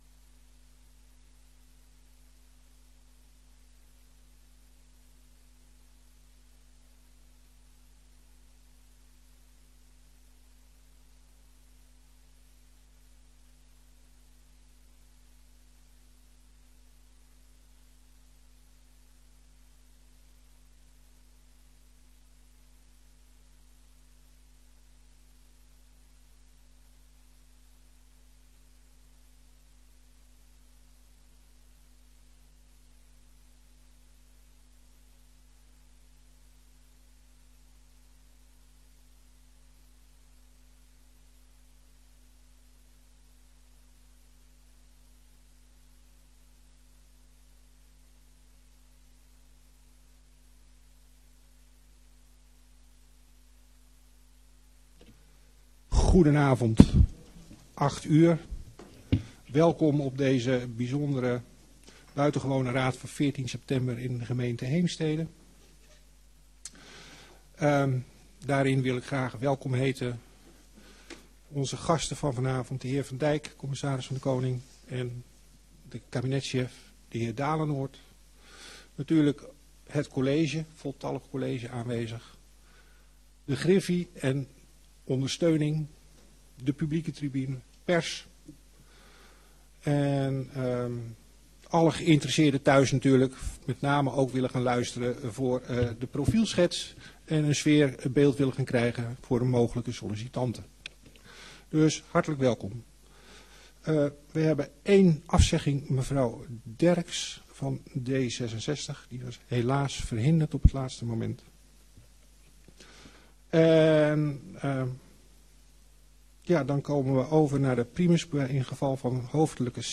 Gemeenteraad 14 september 2023 20:00:00, Gemeente Heemstede
Download de volledige audio van deze vergadering
Locatie: Raadzaal